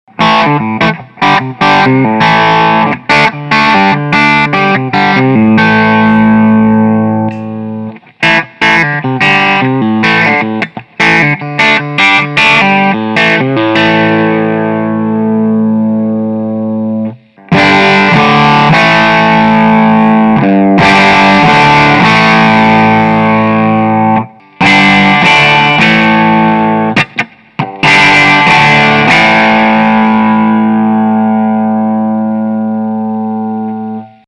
Steg Humbucker vs. Steg Singlecoil
Die lange Mensur erzeugt einen recht perkussiven Ton.
Beim Umschalten auf Singlecoils fällt ein wahrnehmbarer Lautstärkesprung auf.
• Shure SM57
hagstrom_fantomen_test__steg_humbucker_vs_steg_single_coil.mp3